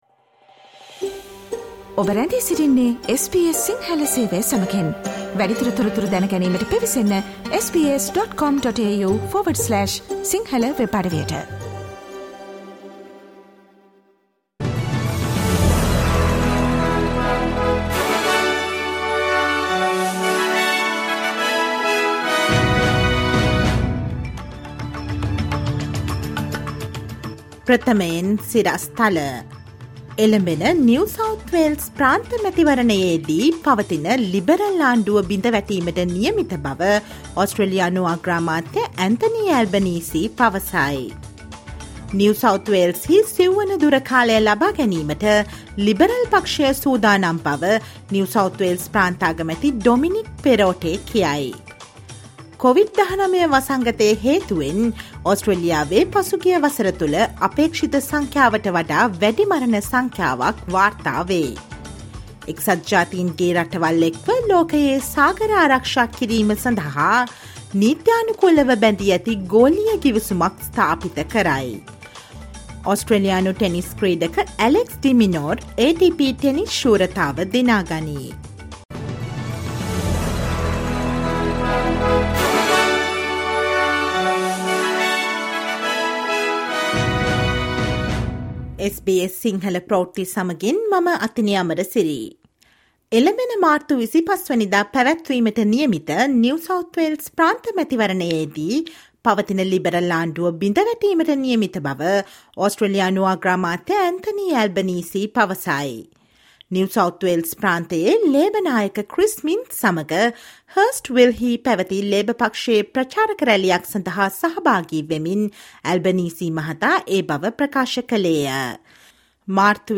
Listen to the latest news from Australia and across the globe, and the latest sports news on SBS Sinhala radio broadcast on 06 th March 2023